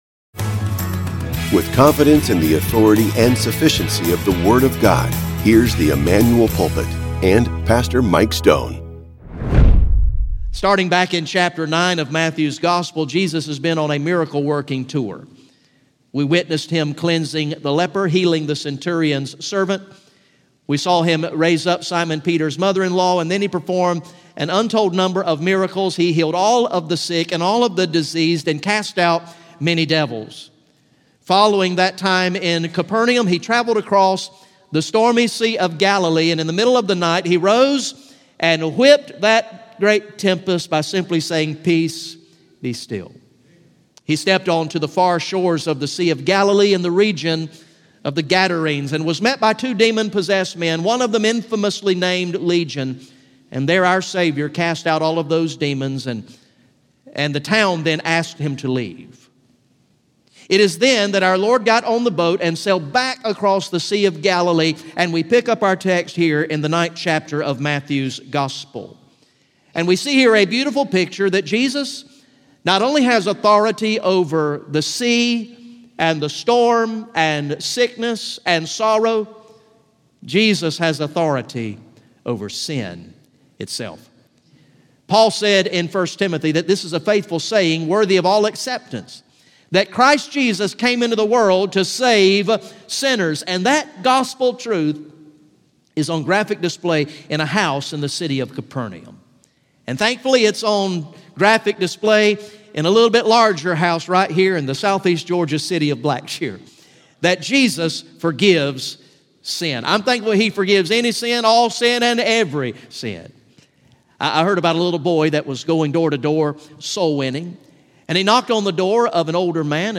Sunday AM